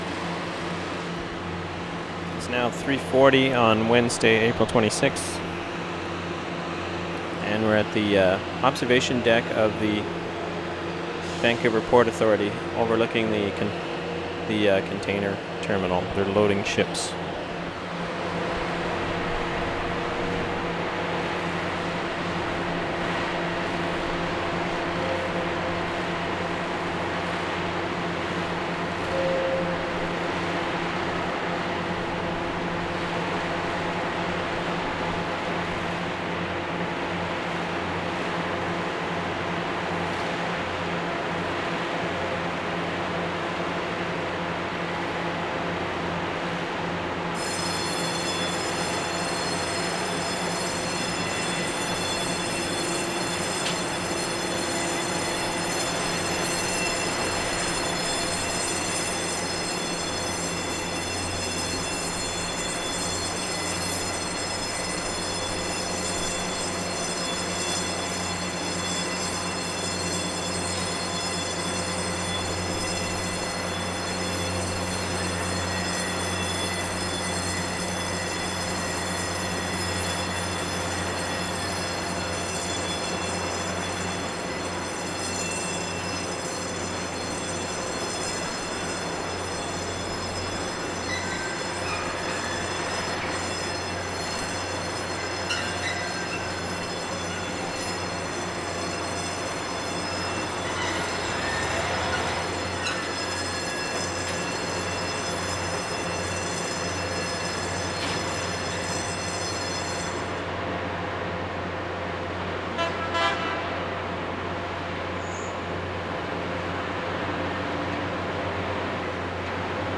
Port of Vancouver, container area 5:23
9. observation deck of Vancouver Port Authority, container loading, large machines, bells at 0:47 and throughout, air horns at 1:51, tractor trailers, forklifts, men yelling, truck horns, air brakes